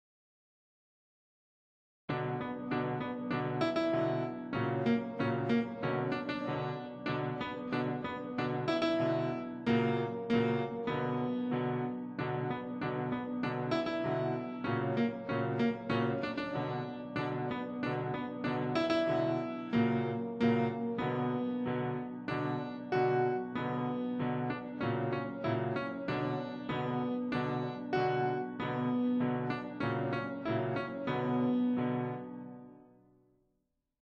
Eigene Version